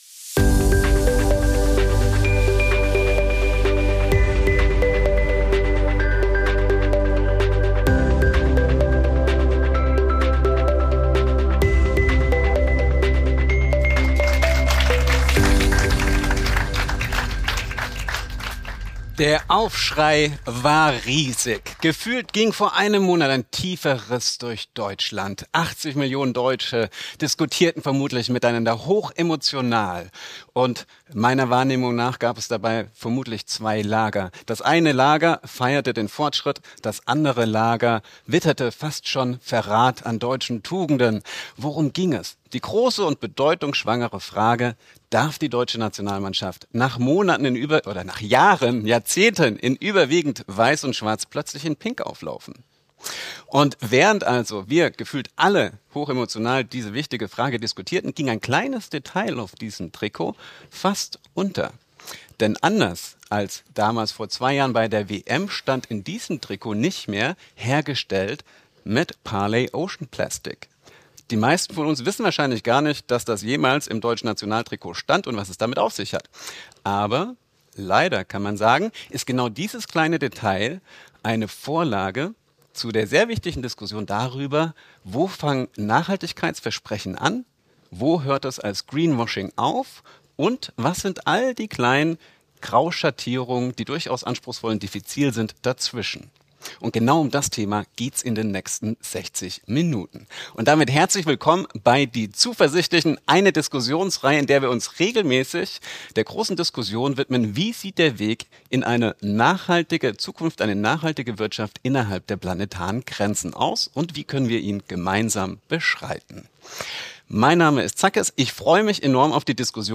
Ein Gespräch über die Suche nach der grünen Wahrheit und echten Lösungen für eine nachhaltige Wirtschaft.